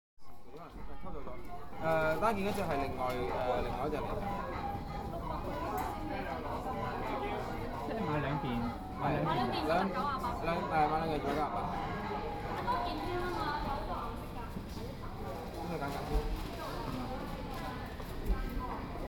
在商店购物时的一段录音，干声，10秒后启动hall混响，说话的3人在我前面3个身位：
shop.mp3